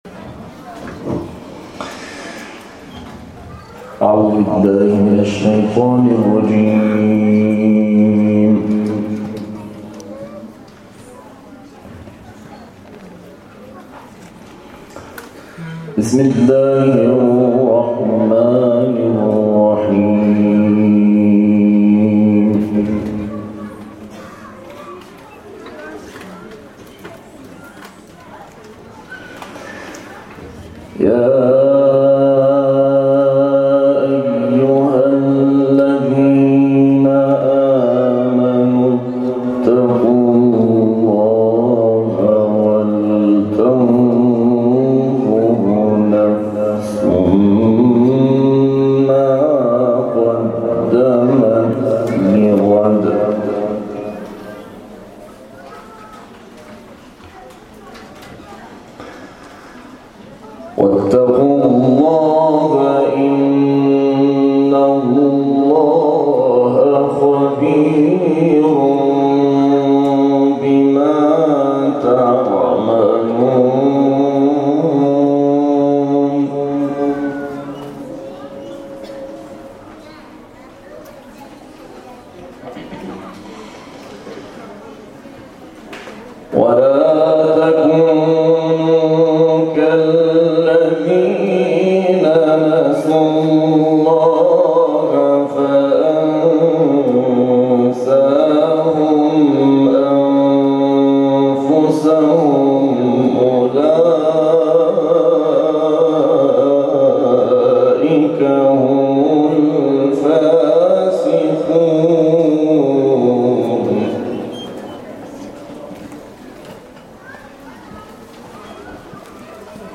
طی این مراسم تلاوت آیات 18 تا 24 سوره مبارکه حشر و آیات ابتدایی سوره مبارکه والشمس و همچنین سوره مبارکه کوثر طنین‌انداز شد.